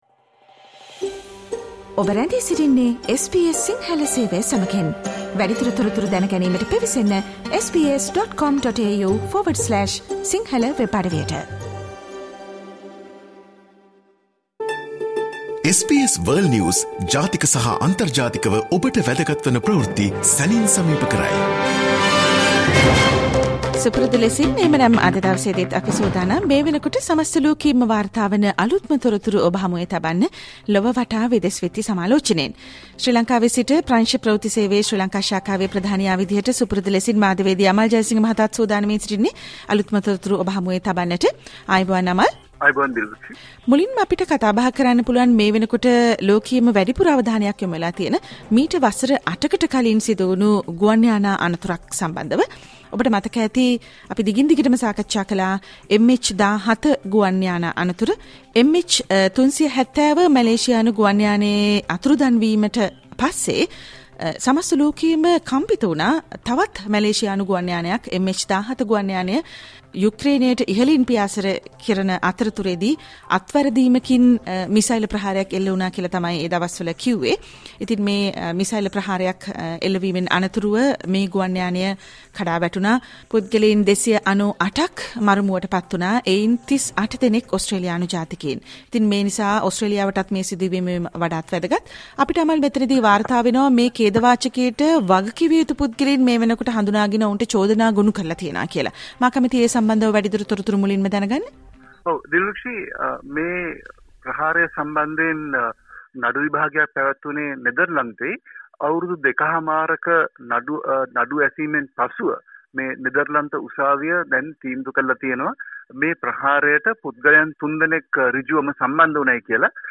listen to the SBS Sinhala Radio weekly world News wrap every Friday Share